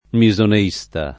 vai all'elenco alfabetico delle voci ingrandisci il carattere 100% rimpicciolisci il carattere stampa invia tramite posta elettronica codividi su Facebook misoneista [ mi @ one &S ta ] s. m. e f. e agg.; pl. m. -sti